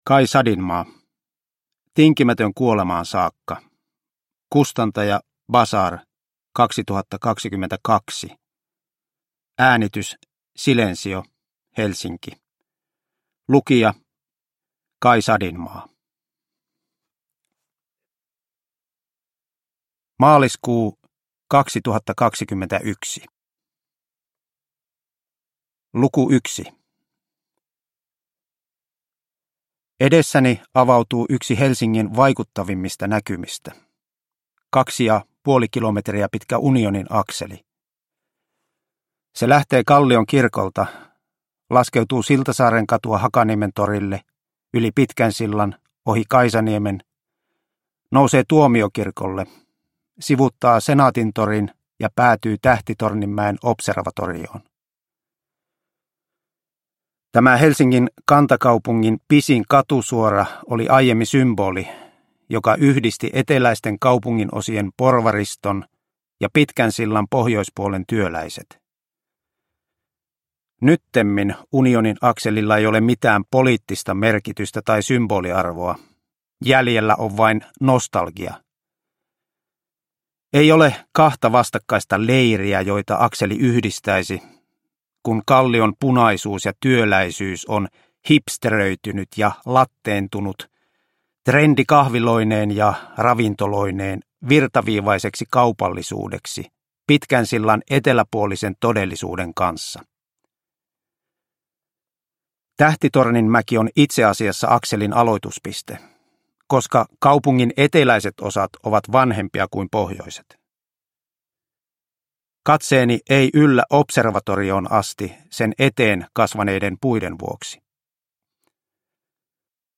Tinkimätön kuolemaan saakka – Ljudbok – Laddas ner